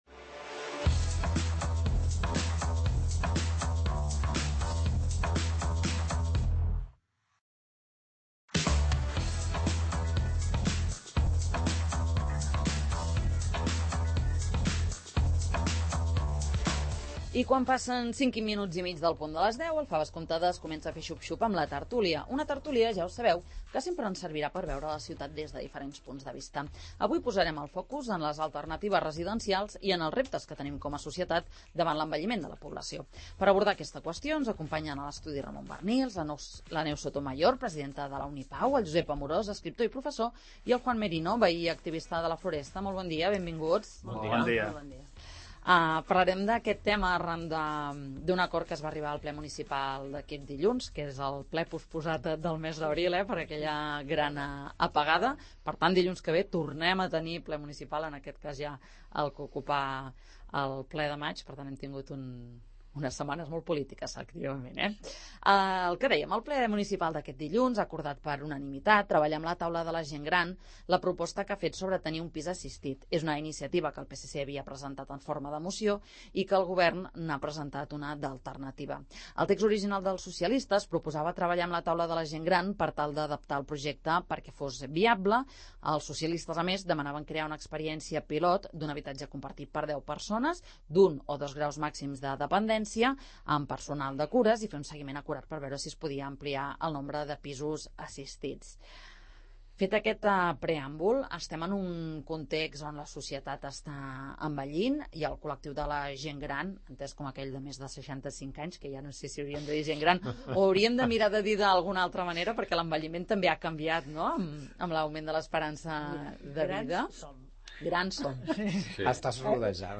Calen reformes p�bliques urgents per fer front a l'envelliment de la poblaci�? En parlem a la tert�lia del 'Faves comptades'